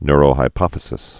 (nrō-hī-pŏfĭ-sĭs, -hĭ-, nyr-)